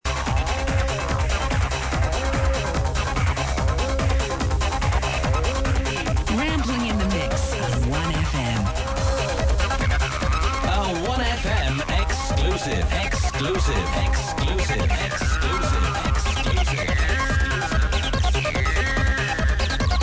Classic trance ID from '90s.